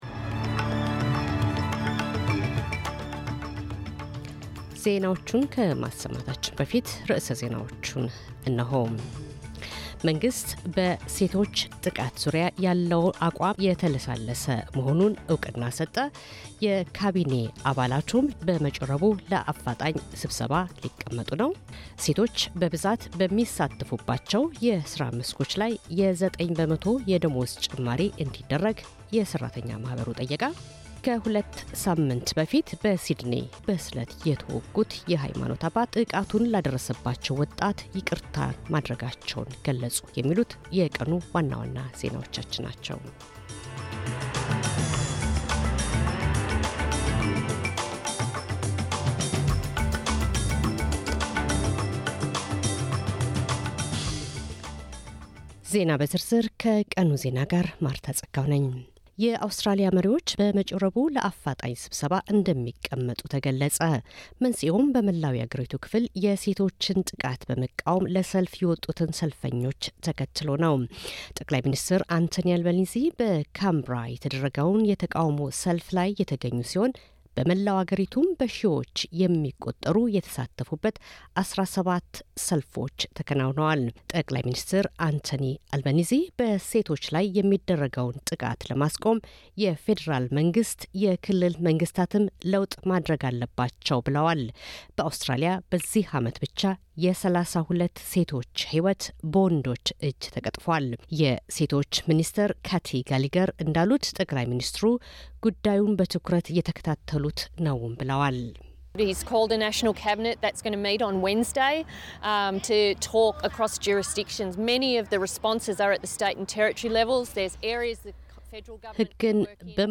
ዜና - በሴቶች ላይ የሚደርሰውን ጥቃት ለመከላከል የአውስትራሊያ ፓርላማ የአስቸኳይ ስብሰባ ጥሪ አደረገ